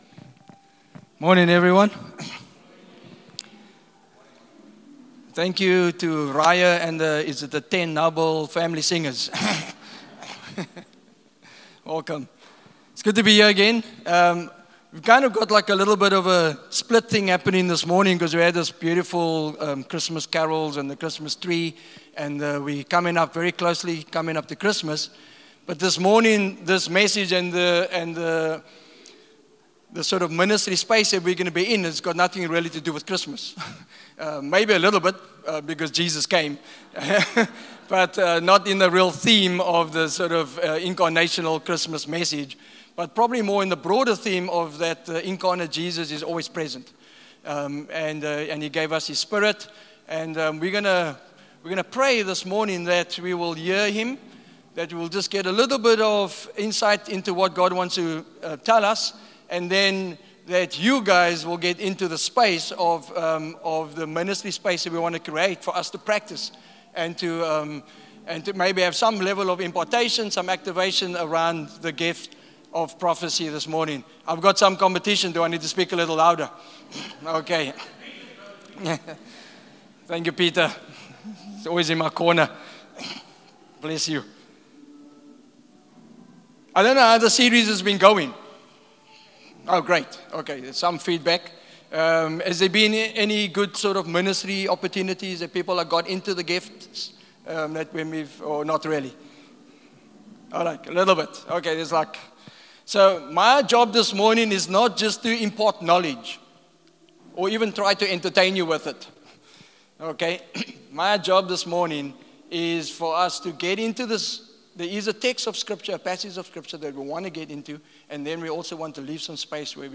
Sunday Service – 22 December
Sermons